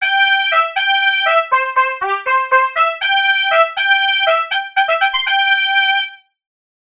Oddílové signály
na trubku nás provázely celým táborem.
Budíček.wav